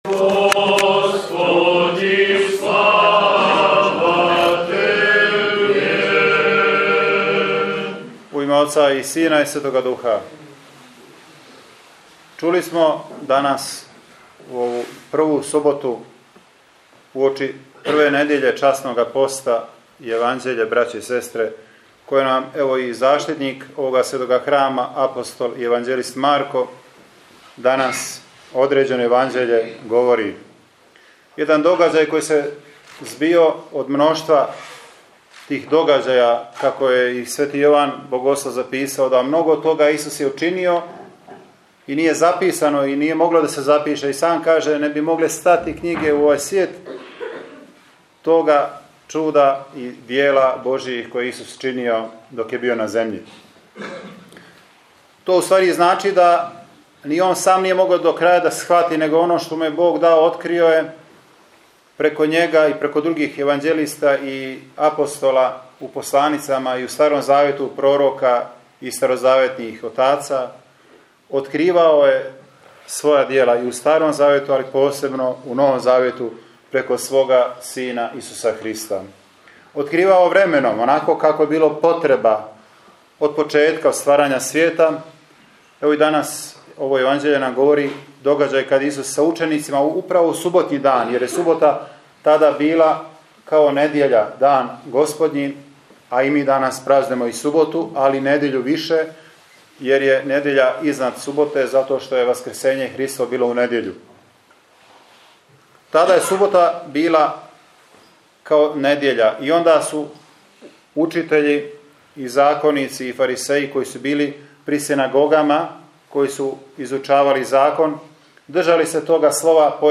У суботу прву великог и часног Поста-Теодорову суботу 12. марта 2022. године Његово Преосвештенство Епископ бихаћко-петровачки г. Сергије предводио је свету архијерејску Литургију у храму светог апостола и јеванђелиста Марка у Ораховљанима.